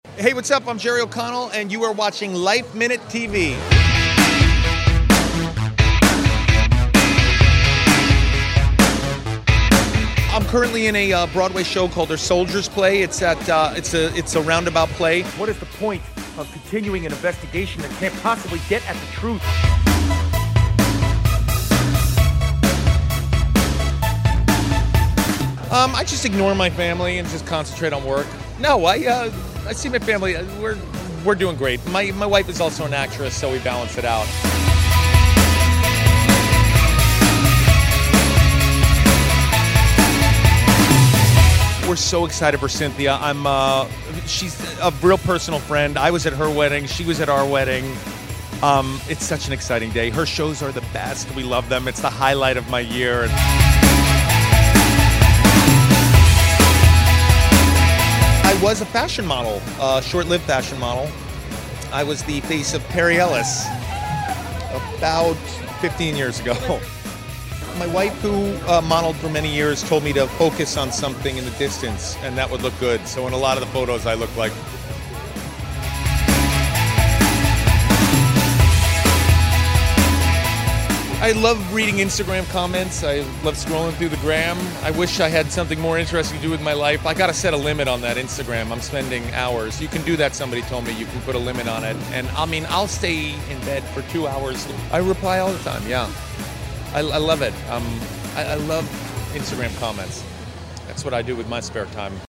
We caught up with the hilarious Jerry O’Connell at his friend, Cynthia Rowley’s Fall 2020 fashion show during New York Fashion Week this month.